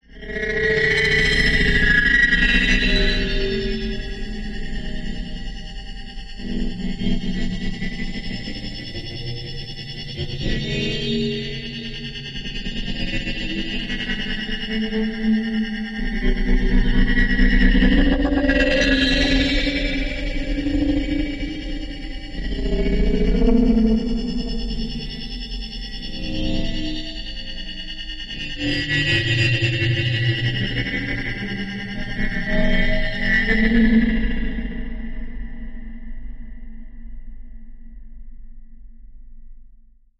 Spinning Latitude Metallic Random Spinning Rusty